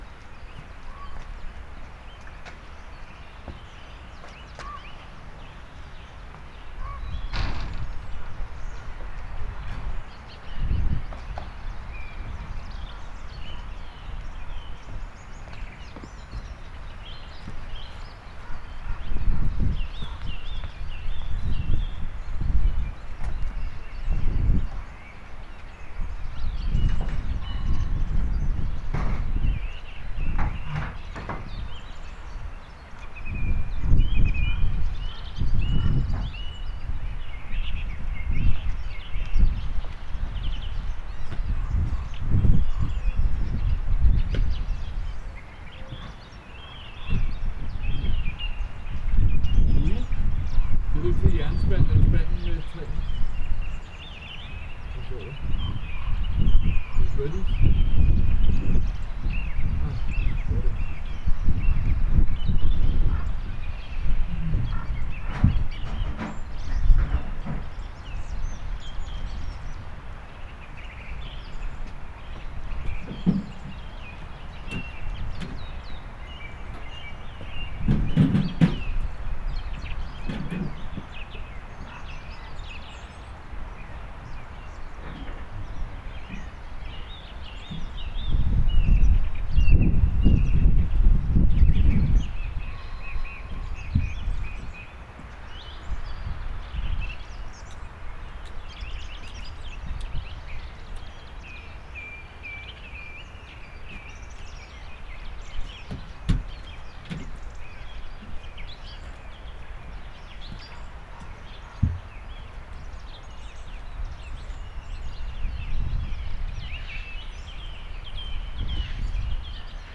birds.mp3